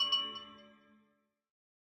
Minecraft Version Minecraft Version 25w18a Latest Release | Latest Snapshot 25w18a / assets / minecraft / sounds / block / amethyst / resonate3.ogg Compare With Compare With Latest Release | Latest Snapshot
resonate3.ogg